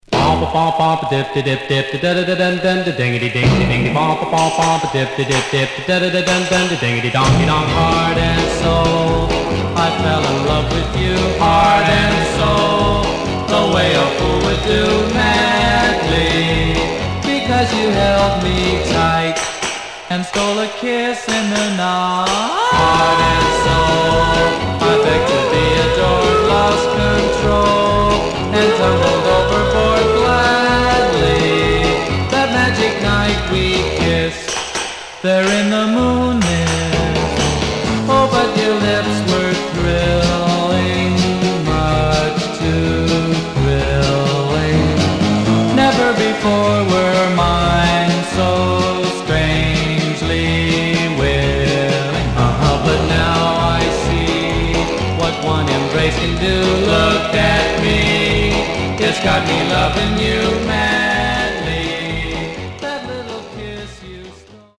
Mono
Surf